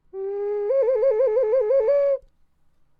• ocarina 1.wav
Short sample from 3d printed ocarina, recorded with a Sterling ST66.
ocarina_1_soQ.wav